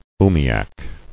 [oo·mi·ak]